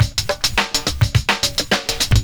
106PERCS01.wav